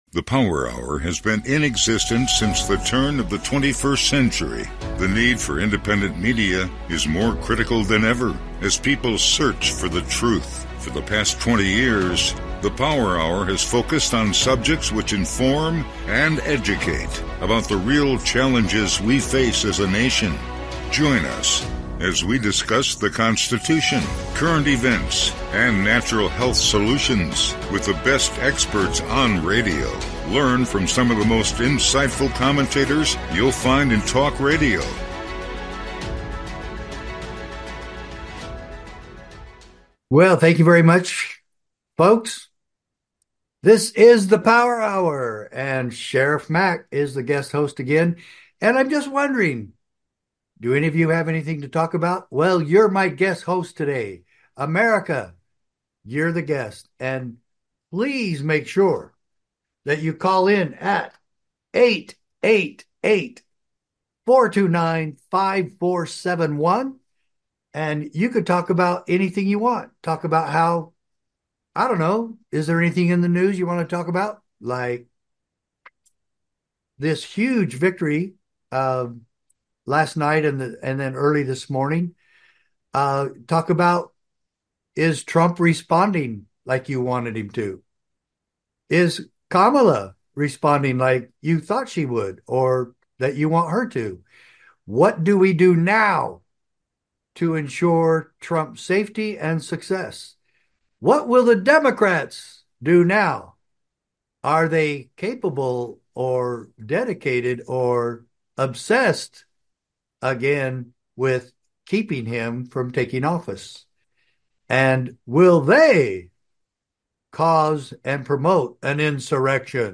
Guest Host, Sheriff Richard Mack